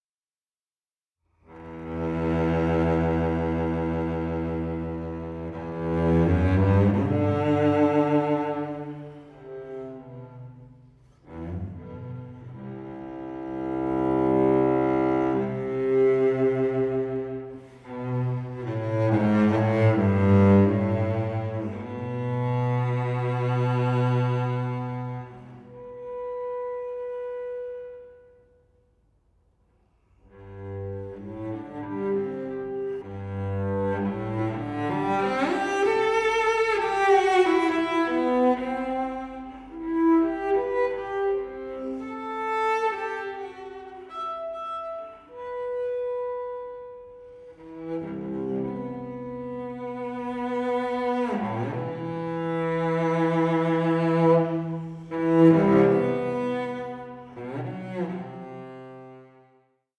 for solo cello